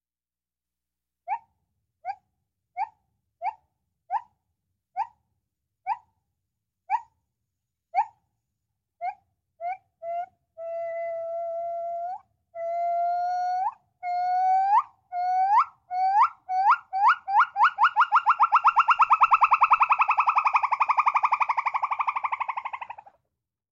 Здесь вы найдете разнообразные крики, песни и коммуникационные сигналы этих обезьян, записанные в естественной среде обитания.
Звуки гиббонов в природе